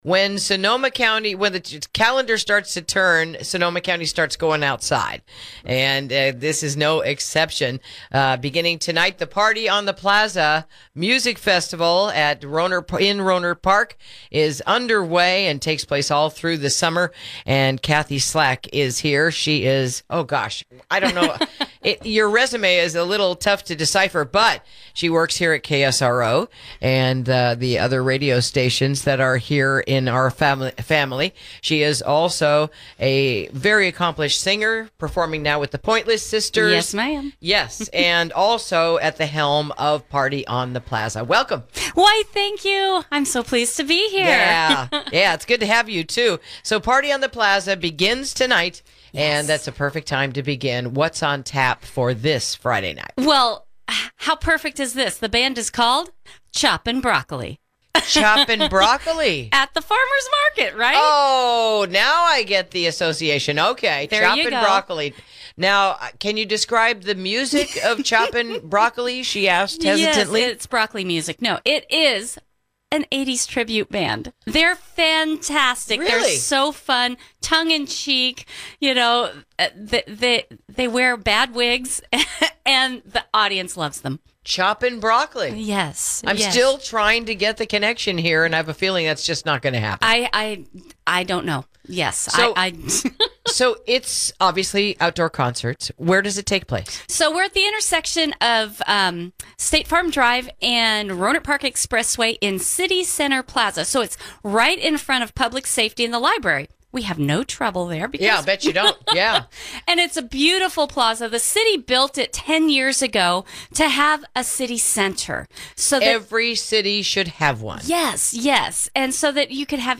Interview: The Rohnert Park “Party on the Plaza” Music Series Kicks Off Tonight